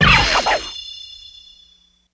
Generic Lasers
Laser Blade Ricochet